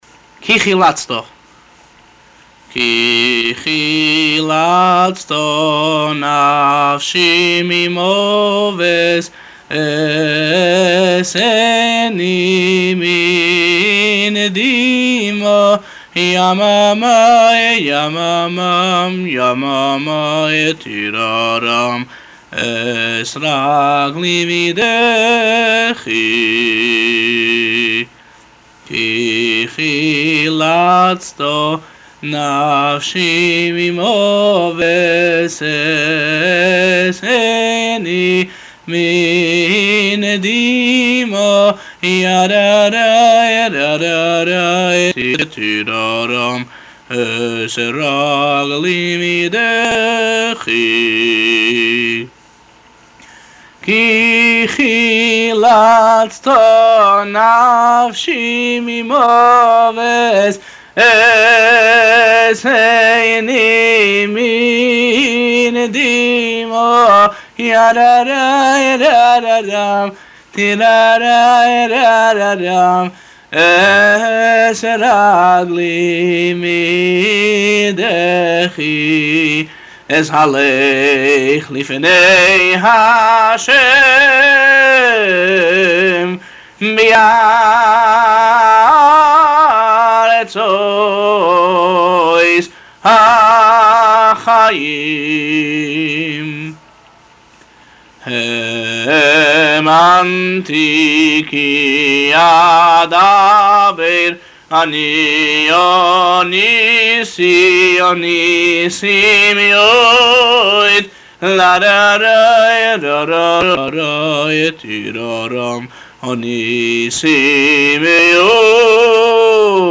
כי חילצת , הוא ניגון חסידי על הפסוקים ב פרק קט"ז בתהלים, אותם אומרים גם ב הלל . אודות הניגון [ עריכה | עריכת קוד מקור ] הניגון כולל ארבעה פסוקים המחולקים ל-2 חלקים, כאשר הלחן בניגון חוזר על עצמו, ובכל חלק חוזרים על הפסוק הראשון שלוש פעמים, והפסוק השני חותם אותו ומנגנים אותו פעם אחת.